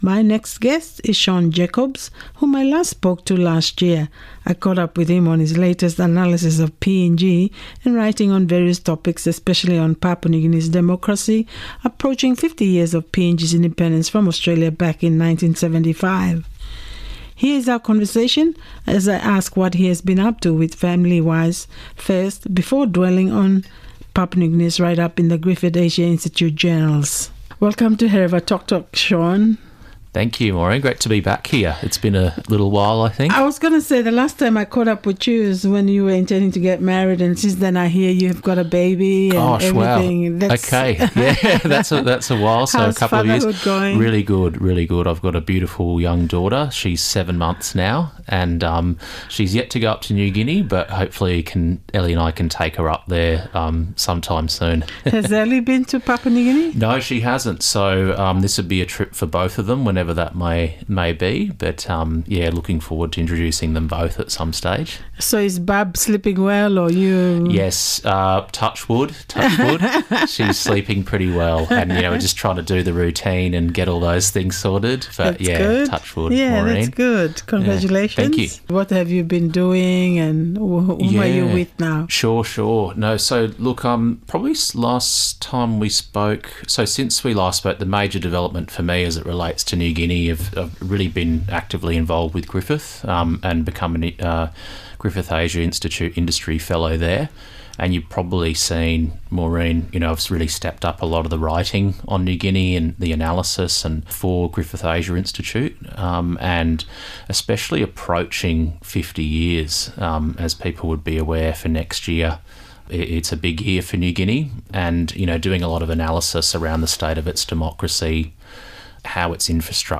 Interview: Radio 4EB Hereva Tok Tok